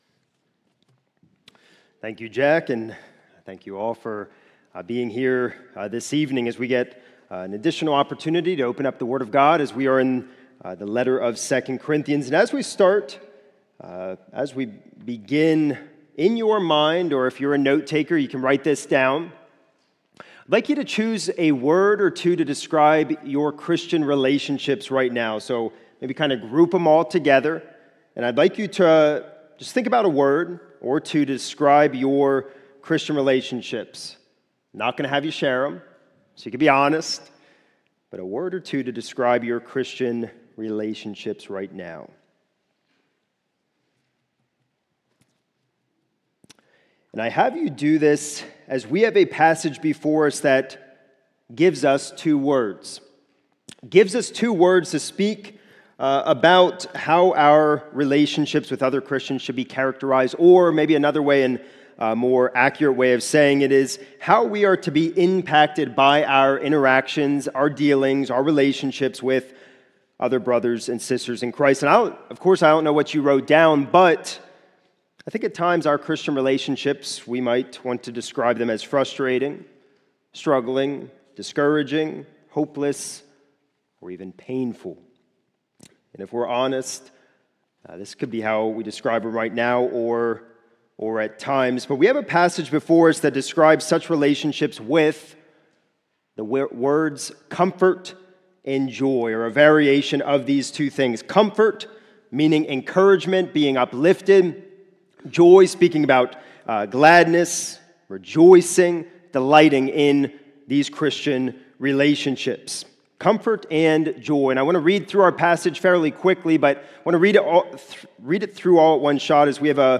This is a sermon recorded at the Lebanon Bible Fellowship Church in Lebanon